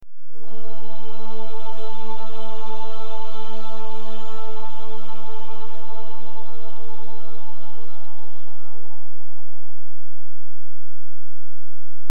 Choir fadeout loop Dm 160 bpm (WAV file)
Relevant for: loops,, choir,, chorus,, singing,, church,.
Try preview above (pink tone added for copyright).